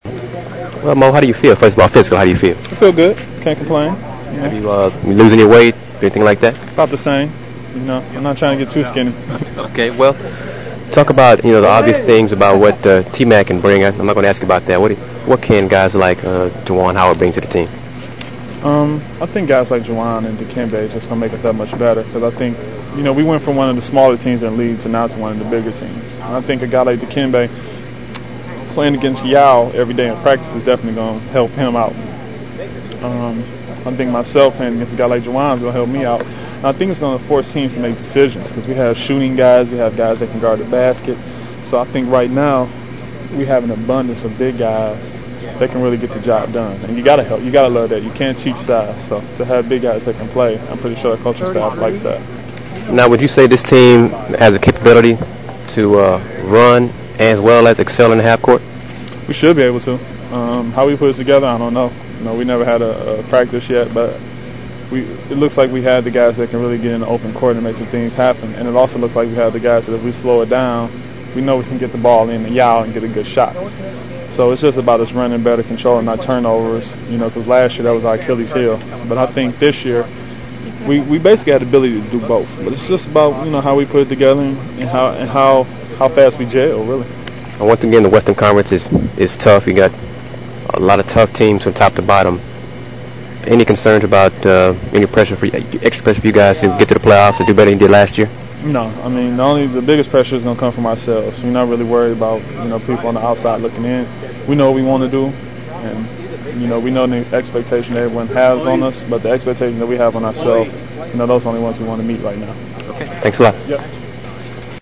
Rockets Media Day (October 1, 2004)
Interview w/ Maurice Taylor: "...one of the